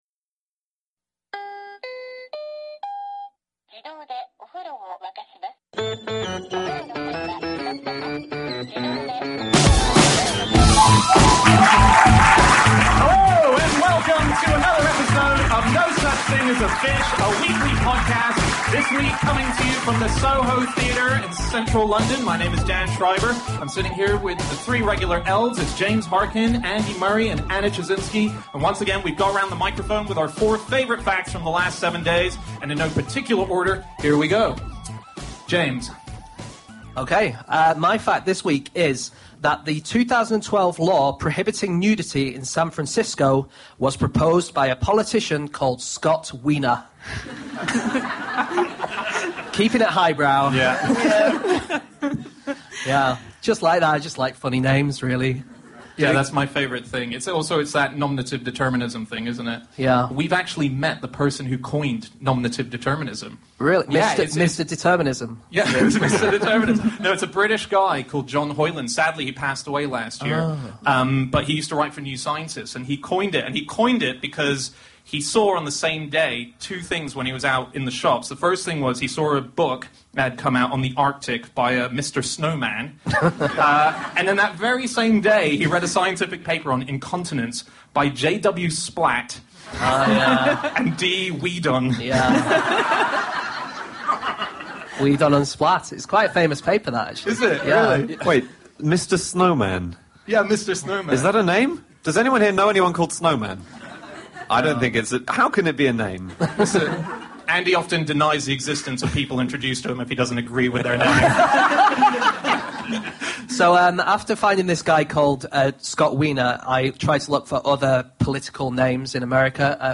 Live at the Soho Theatre